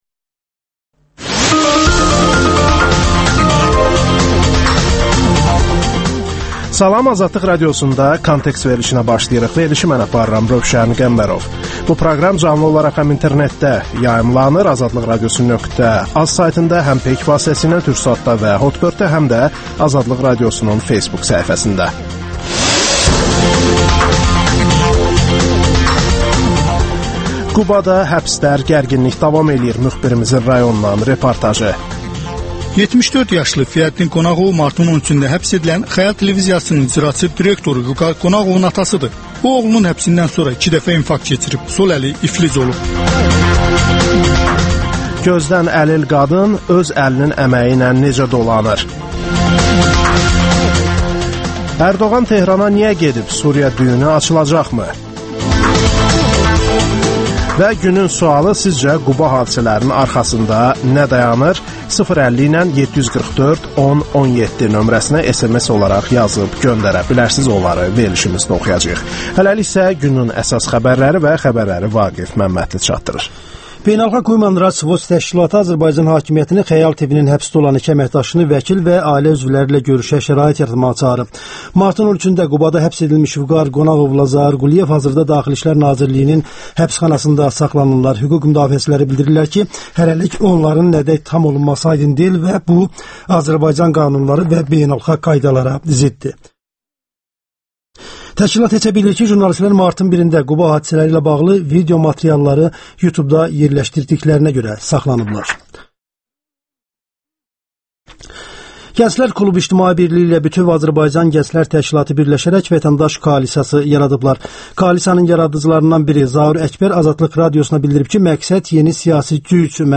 Kontekst - Qubada həbslər davam edir [Müxbirimizin rayondan reportajı]
Müsahibələr, hadisələrin müzakirəsi, təhlillər